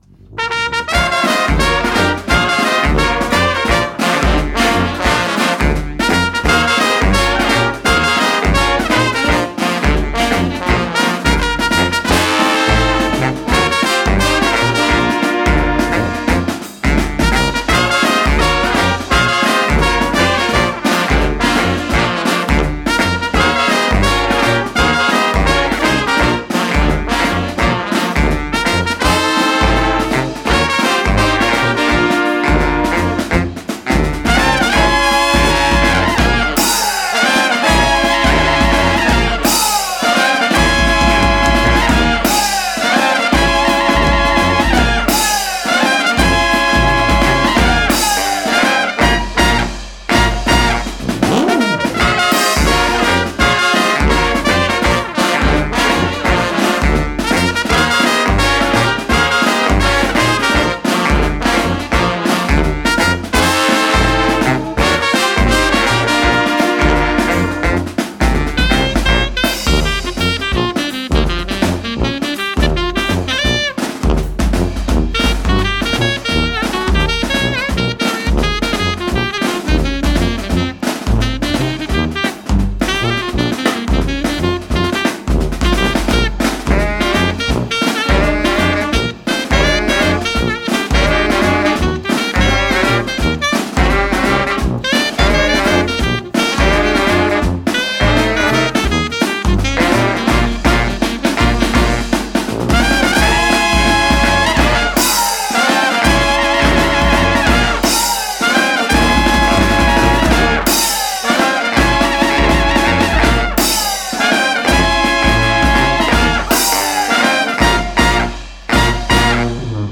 De la fanfare, qui groove en brass band, et de l’énergie à revendre pour ce groupe rodée à faire swinguer et jumper !!
Un répertoire festif éclectique, teinté de sonorités de la Nouvelle Orléans,